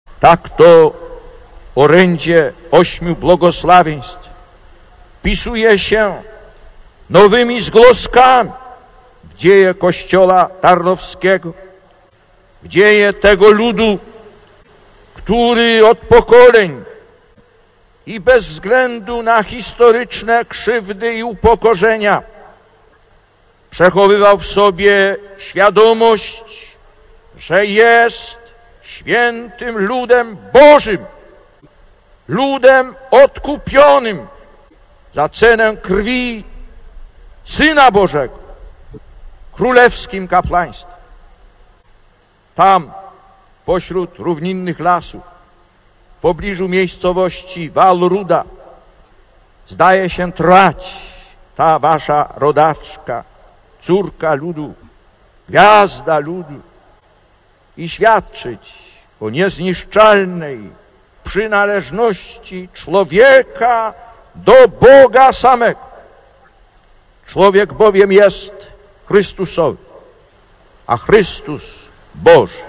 Lektor: Z homilii podczas Mszy św. beatyfikacyjnej Karoliny Kózkówny (Tarnów, 10 czerwca 1987 – nagranie): „"Błogosławieni jesteście" (…).